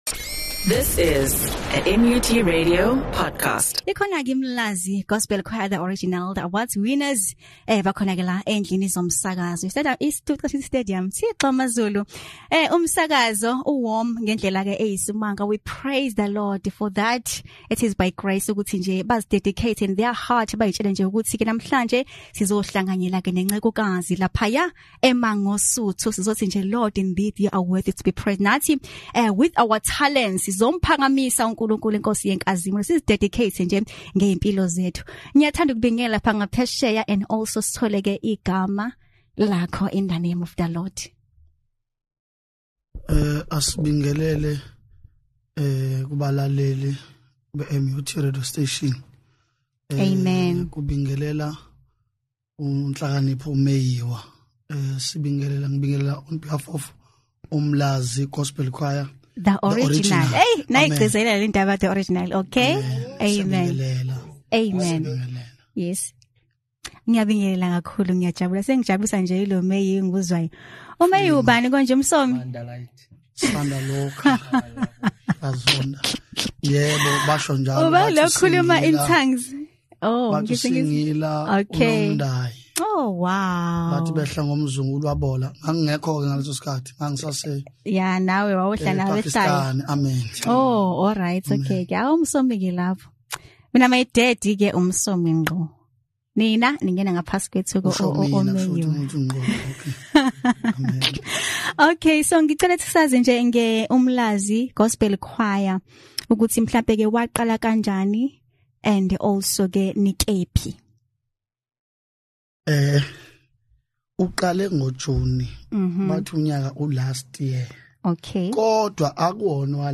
A Successful Interview we had, with uMlazi Gospel Choir the Original. This newly awards winners mentioned during the interview of the importance of maintaining a good character and also to keep the Faith.